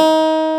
CLAV G3+.wav